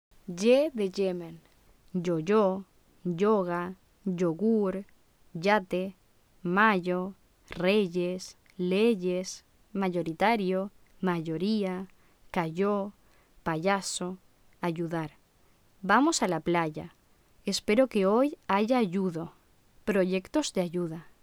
/ʝ/ Fricativa palatal sonora
El fonema fricativo palatal sonoro /ʝ/ (que se corresponde con la grafía < y > ej. yate, ayer, reyes) cuenta con dos variantes o alófonos:
[ʝ] de «voy a Yemen»